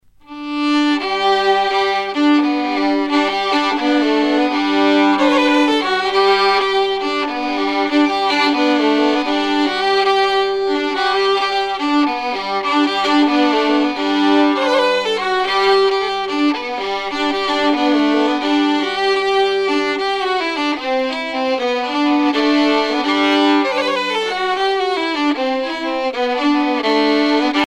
Chants de marins traditionnels
Pièce musicale éditée